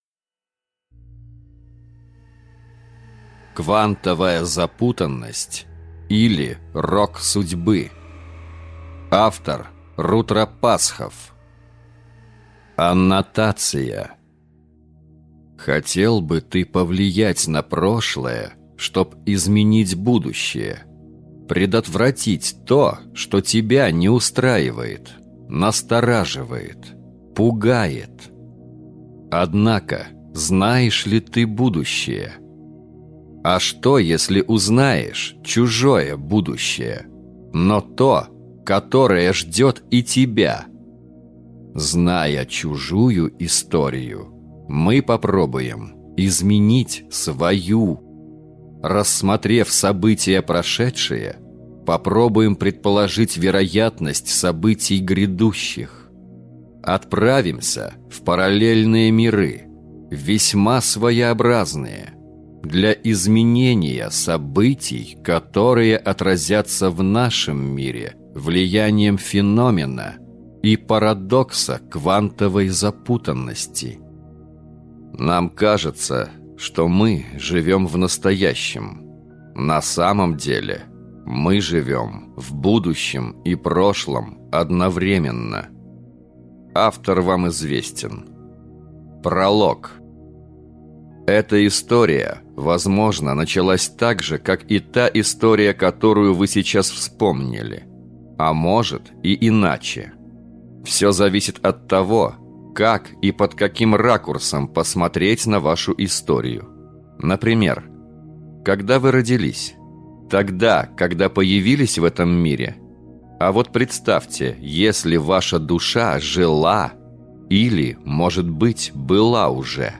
Аудиокнига Квантовая запутанность, или Рок судьбы | Библиотека аудиокниг